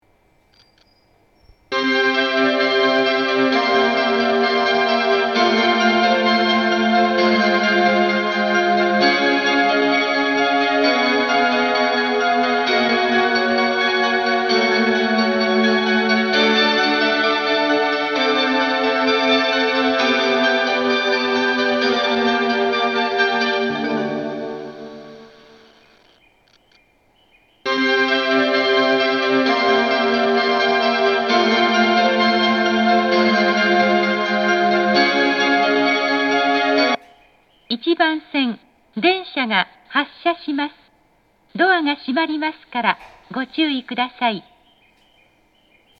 放送系統は上下兼用です。
発車メロディー
奇跡の1.4コーラスです!時間調整する列車で鳴りました。